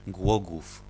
Głogów ([ˈɡwɔɡuf]
Pl-Głogów.ogg.mp3